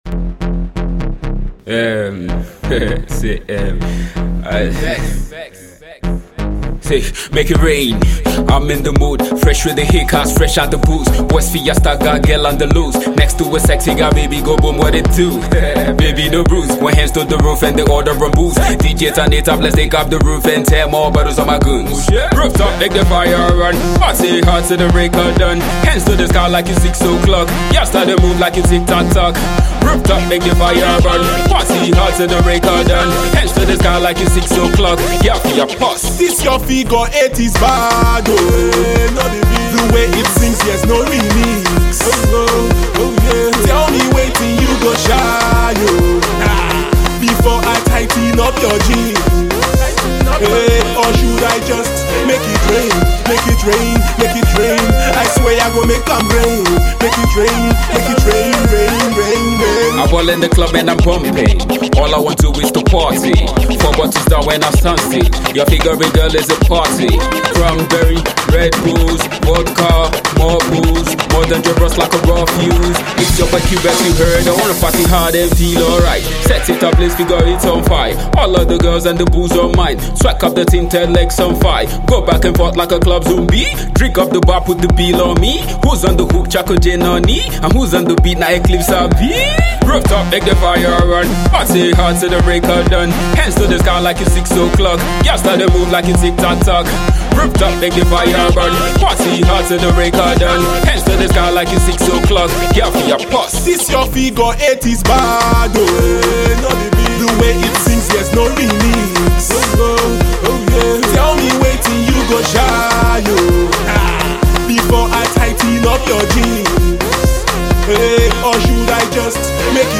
a Ragga/Pop Hybrid, intended for the clubs